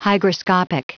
Prononciation du mot hygroscopic en anglais (fichier audio)
Prononciation du mot : hygroscopic